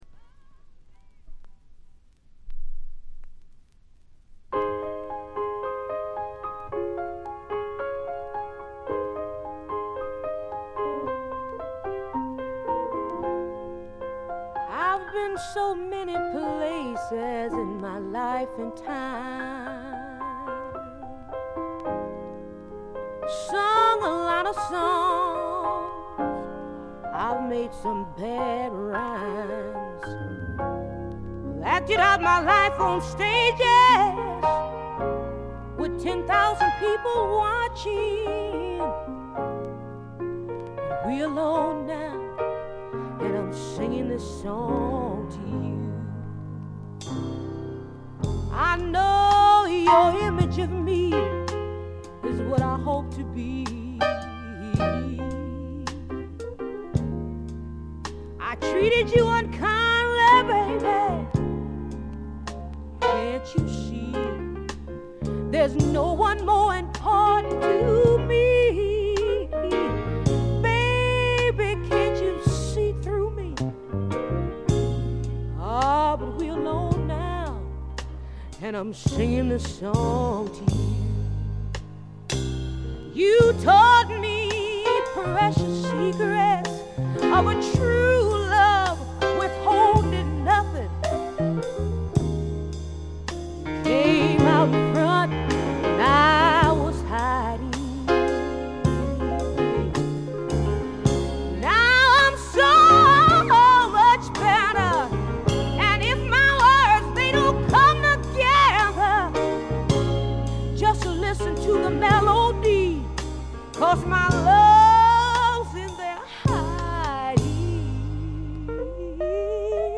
試聴曲は現品からの取り込み音源です。
Recorded At - A&M Studios